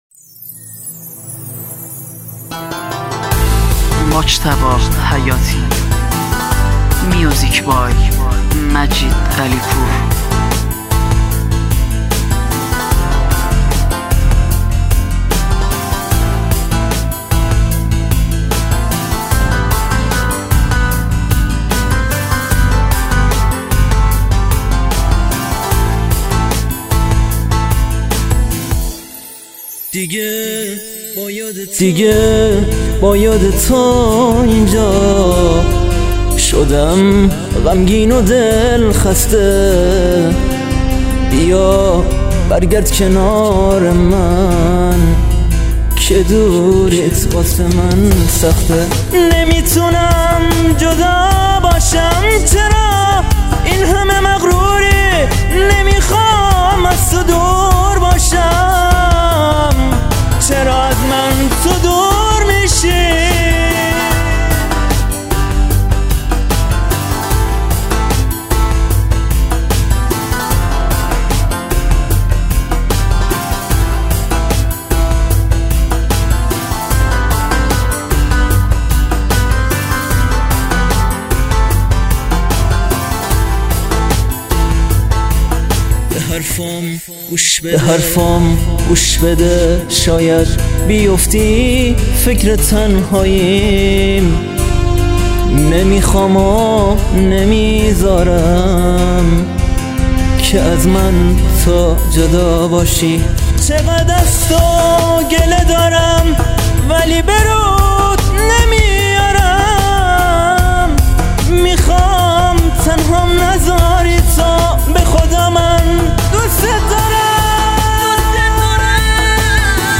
دسته بندي : تک آهنگ ,